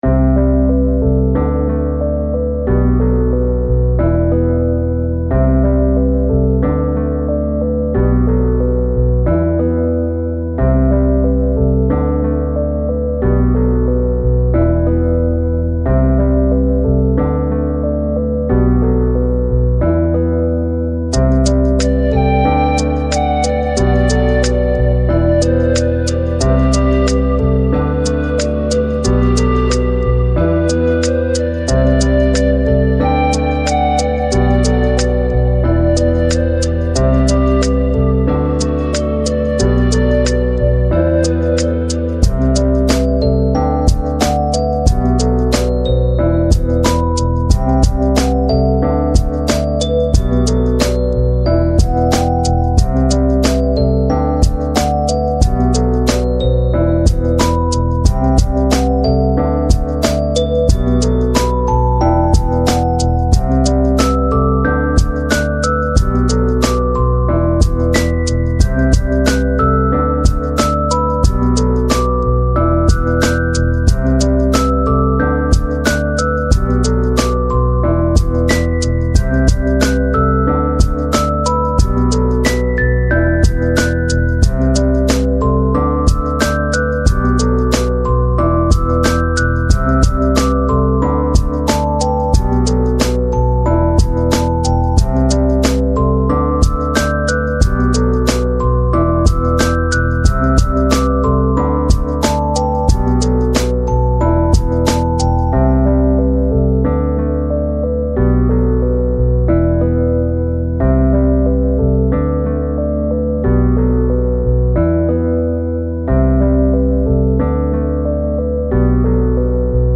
Genre: Lo-Fi